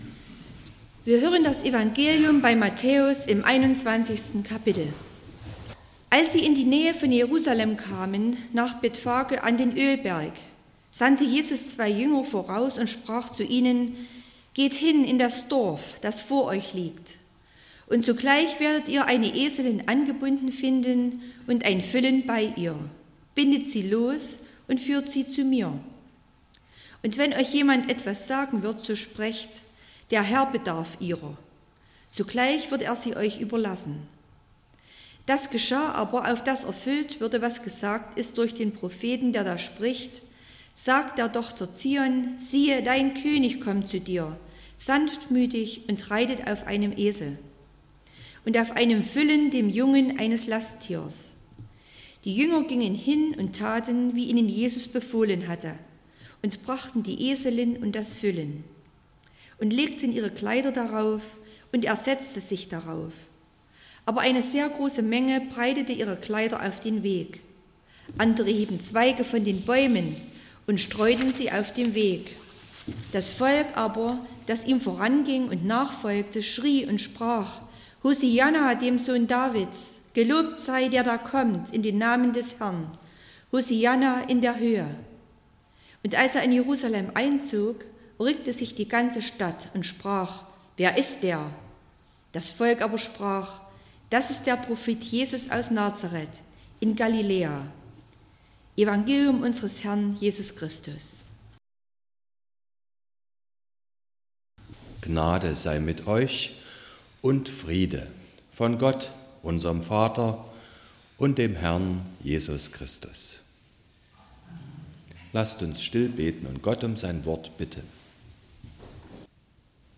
08.12.2024 – Gottesdienst
Predigt (Audio): 2024-12-08_Der_Friedefuerst_kommt.mp3 (20,2 MB)